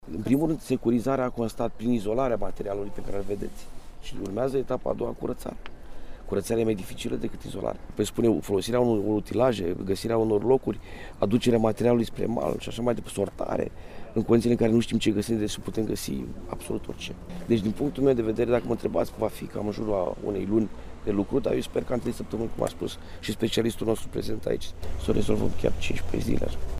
La rândul său, prefectul judeţului Neamţ, Adrian Bourceanu, a afirmat că astăzi, Direcţia de Sănătate Publică va lua probe de apă, dar, indiferent de rezultate, autorităţile sunt contratimp, pentru scoaterea resturilor aduse de viitură.